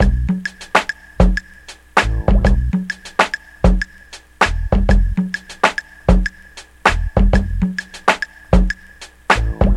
Track 47 (SV Tour) Bongo + Drum Loop.wav